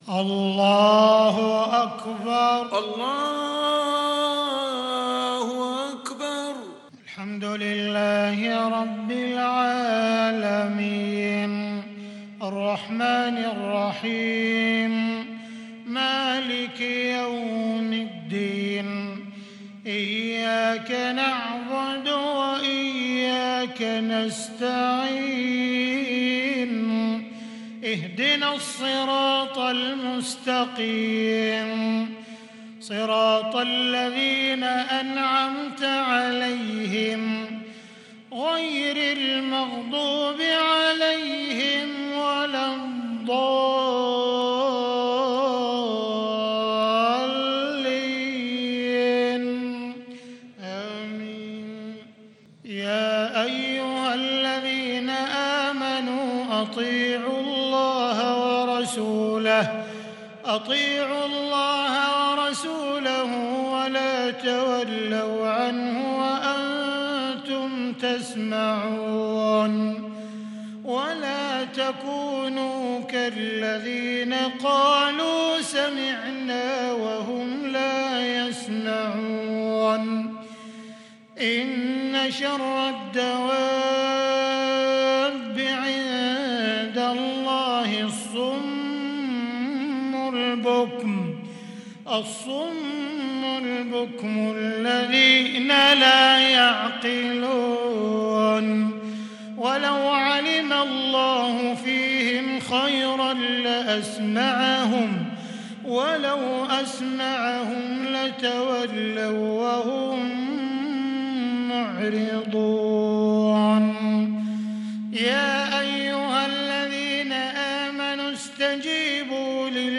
صلاة العشاء للشيخ عبدالرحمن السديس 9 ربيع الآخر 1442 هـ
تِلَاوَات الْحَرَمَيْن .